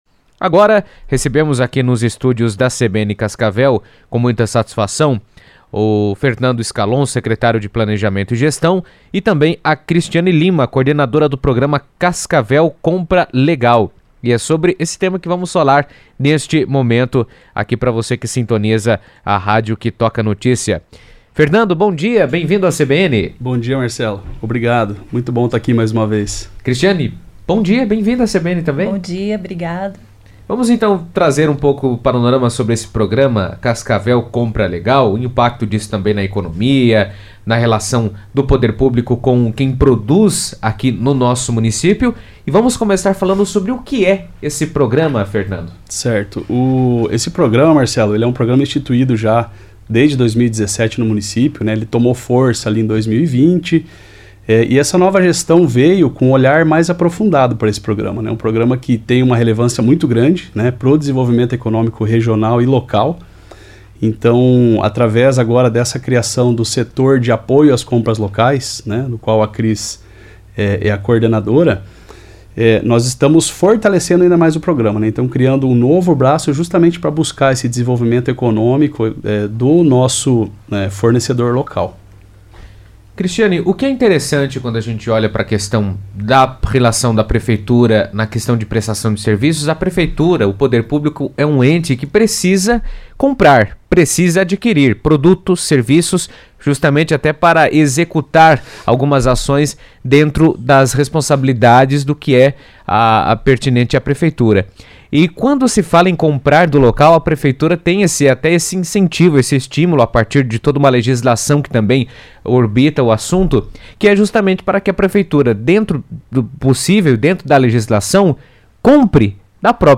estiveram na CBN destacando a importância da ação para o desenvolvimento econômico da cidade.